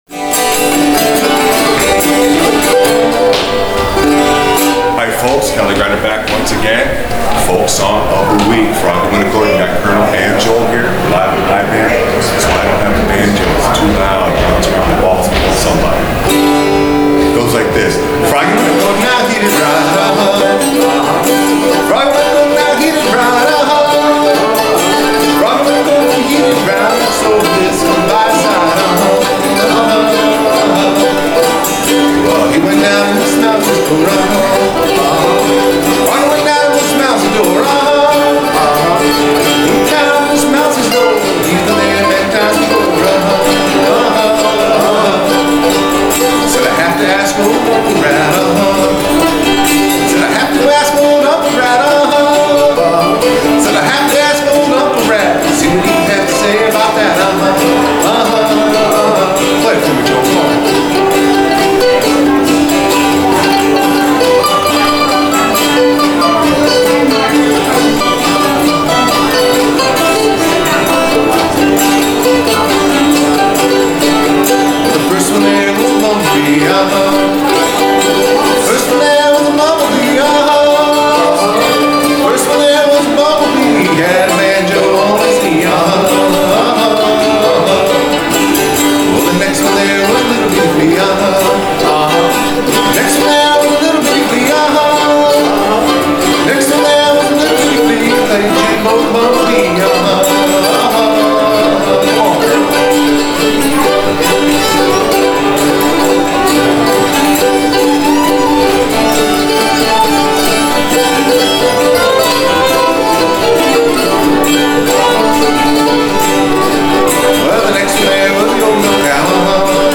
Clawhammer BanjoFolk Song Of The WeekFrailing BanjoInstruction
It’s live on tape again from iBAM! with me